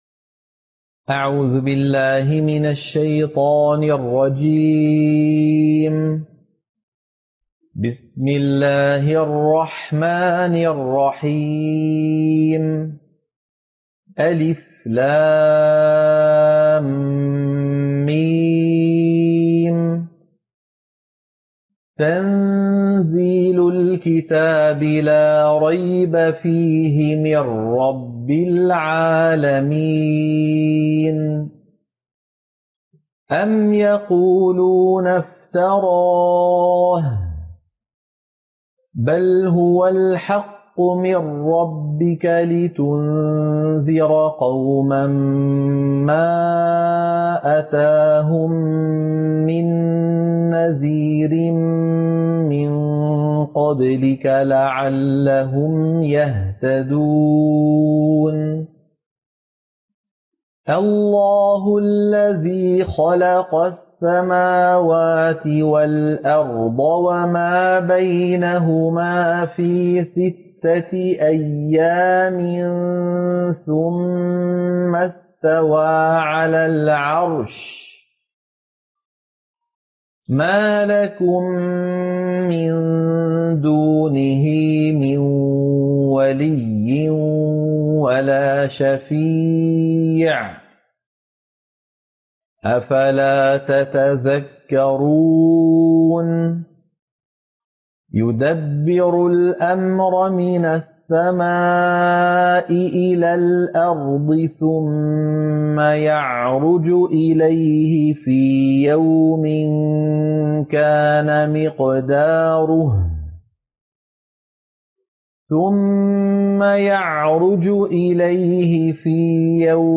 سورة السجدة - القراءة المنهجية